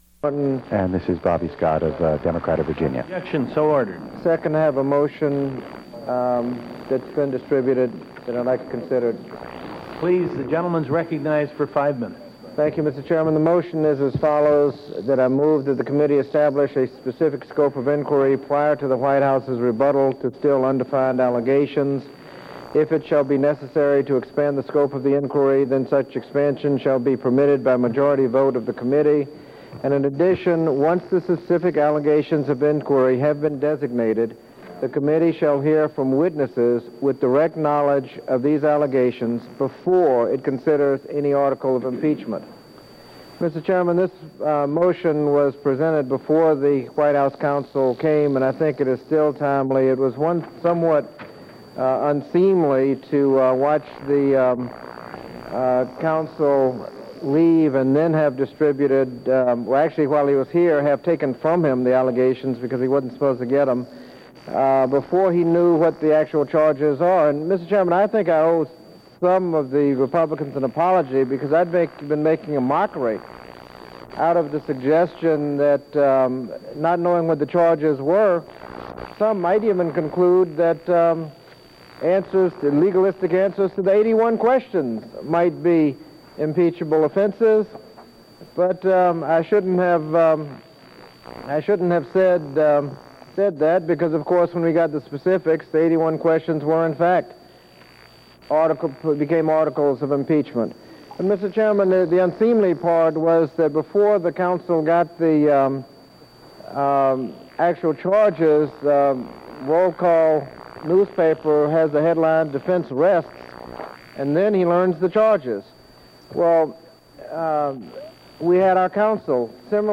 David Schippers, Majority Counsel for the House Judiciary Committee examining the impeachment of President Clinton, presents closing arguments to the committee